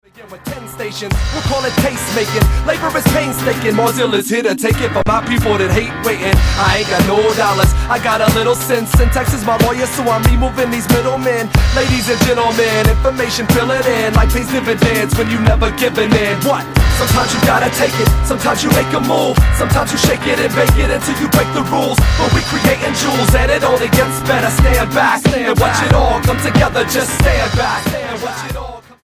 STYLE: Hip-Hop
hard-hitting beats and creative grooves
uncompromising rhymes